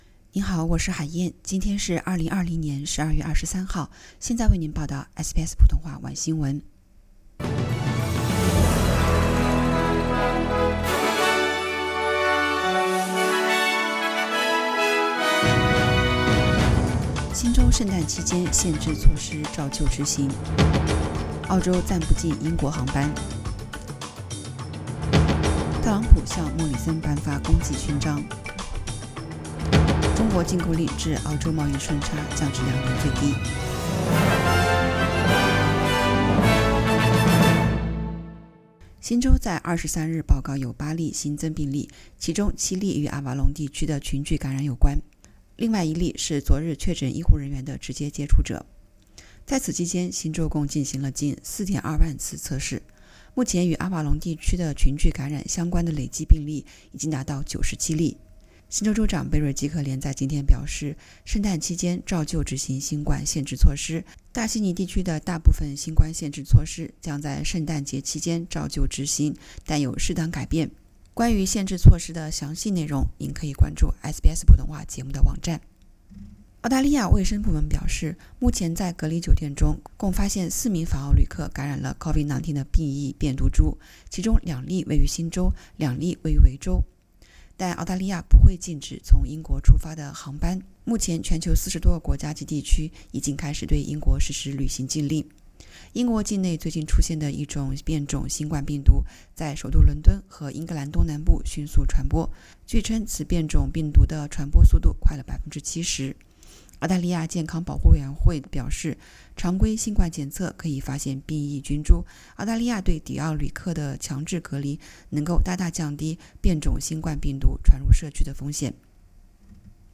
SBS晚新聞（12月23日）